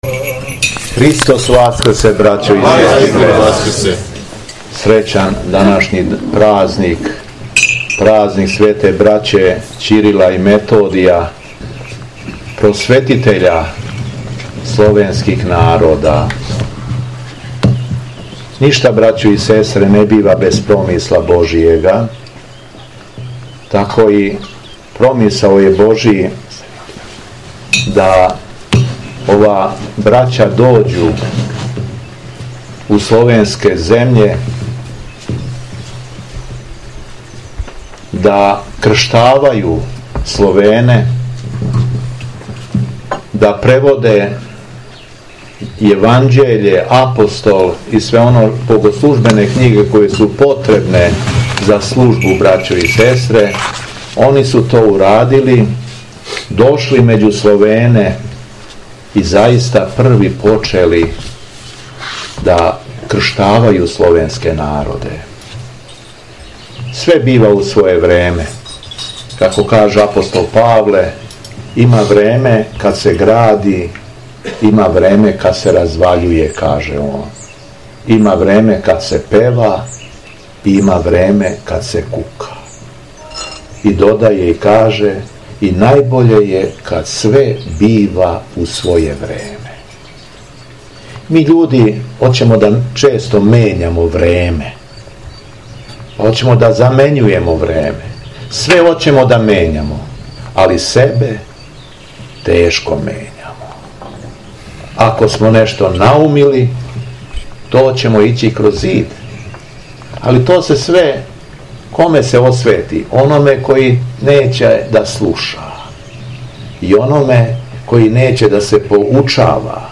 У петак, треће недеље након Васкрса, а на празник Свете браће Ћирила и Методија Његово Преосвештенство епископ шумадијски Господин Јован служио је Свету Литургију у манастиру Саринац.
Беседа Његовог Преосвештенства Епископа шумадијског г. Јована